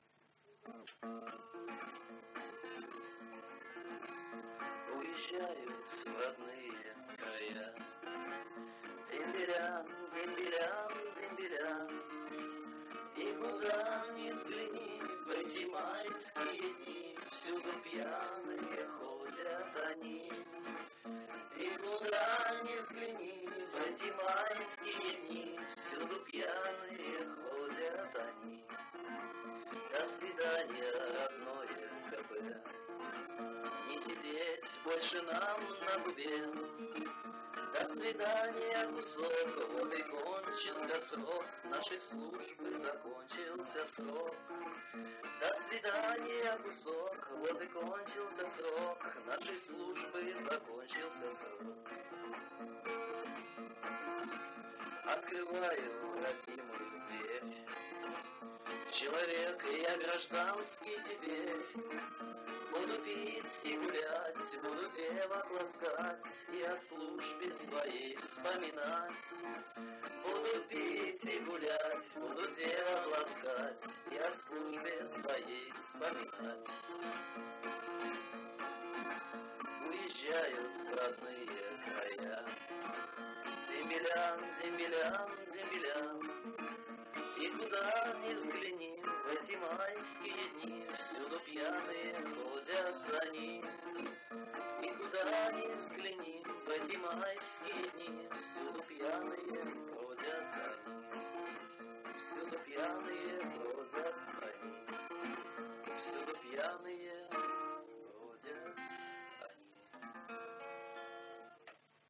Вокал
бэк-вокал